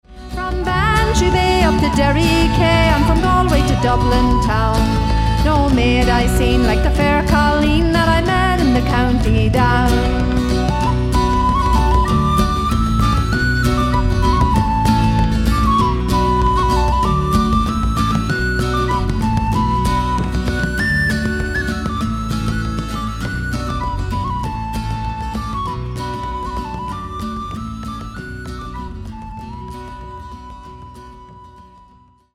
Guitar and vocals
Harp, Whistles and Flute
Bass Guitar